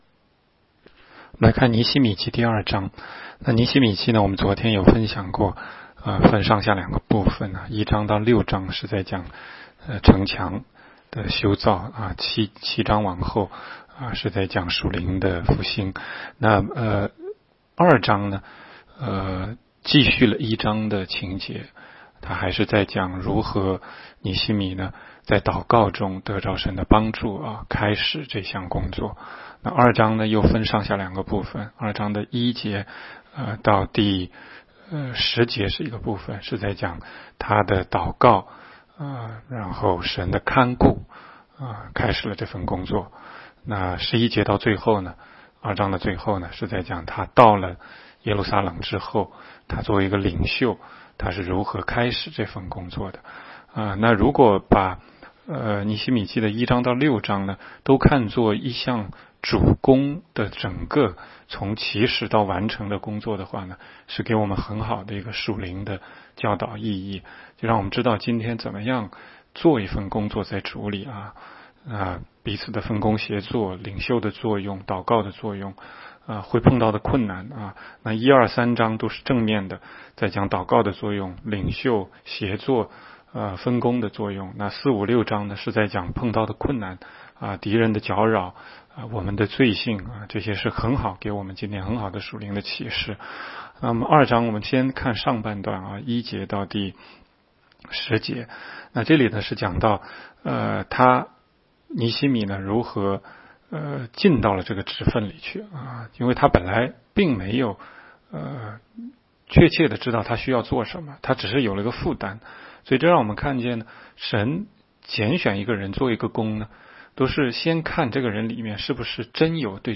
16街讲道录音 - 每日读经-《尼希米记》2章